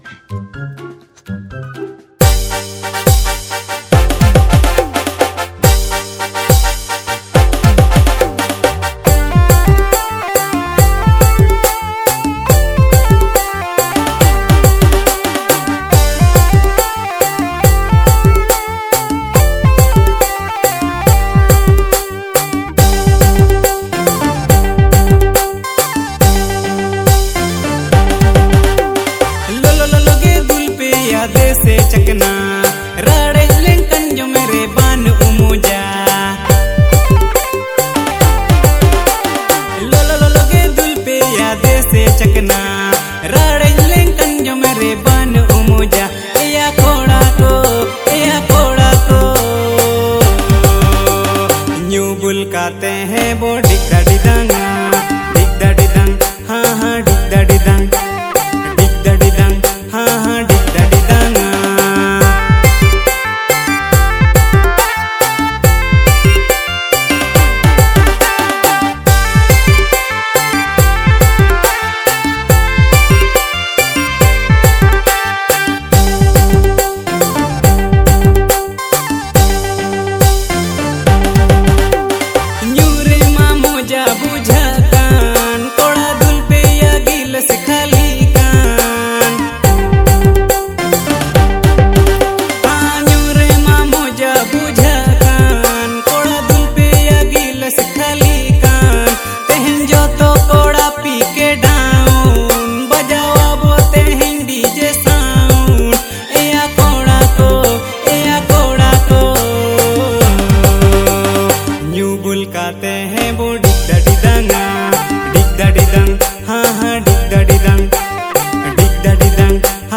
• Male Artist